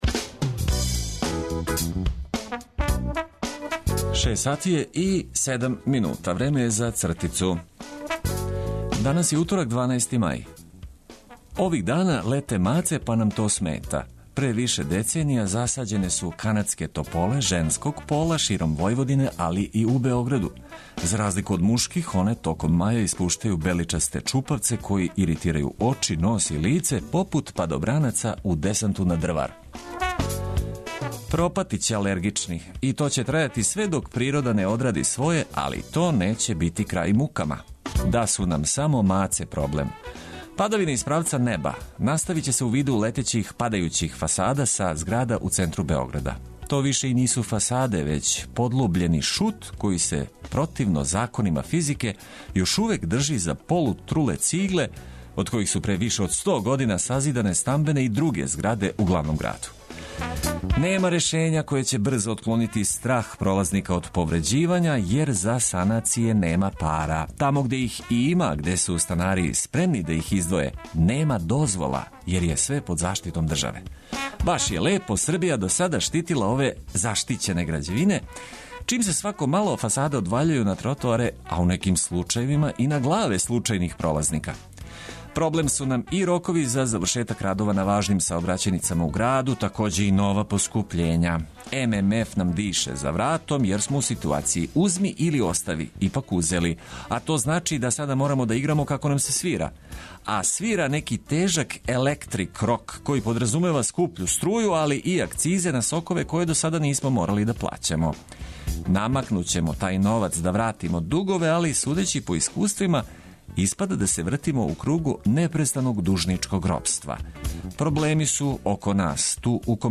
Нека дан почне уз обиље добре музике коју ћемо прошарати корисним информацијама уз пријатне гласове ваших радио пријатеља.